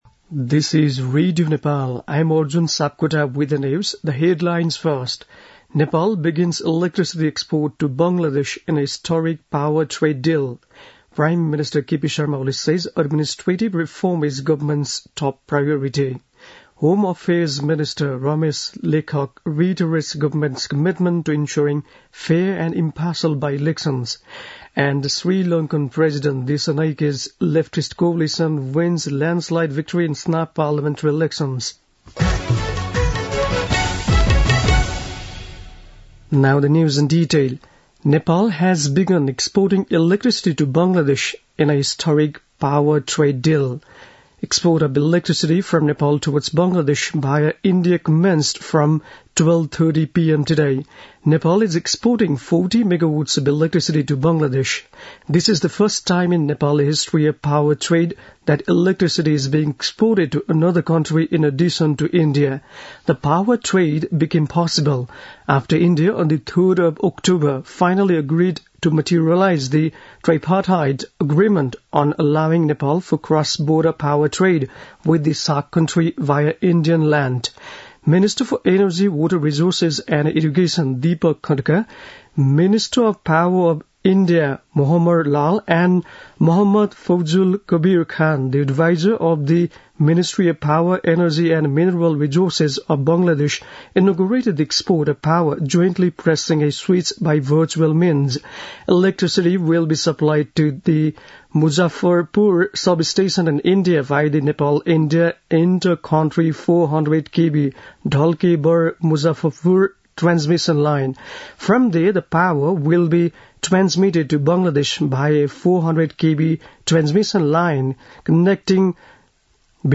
बेलुकी ८ बजेको अङ्ग्रेजी समाचार : १ मंसिर , २०८१
8-pm-english-news-7-30.mp3